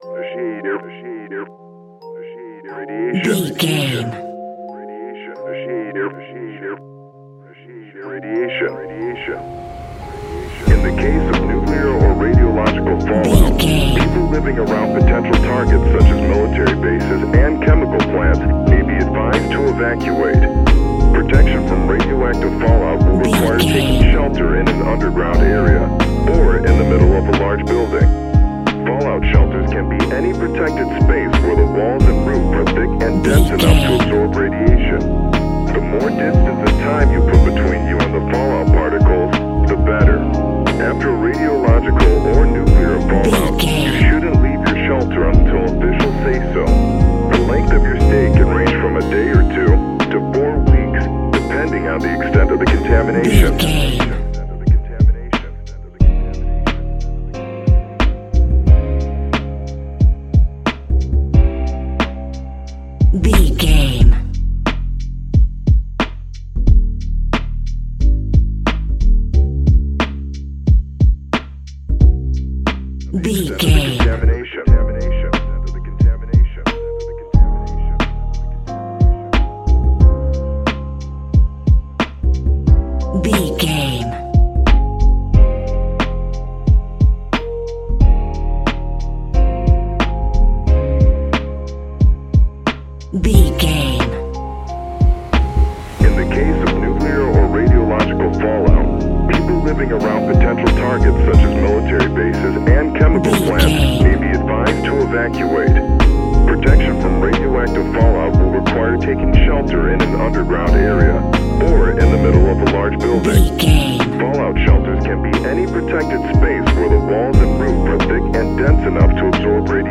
Ionian/Major
C♭
chilled
laid back
Lounge
sparse
new age
chilled electronica
ambient
atmospheric
instrumentals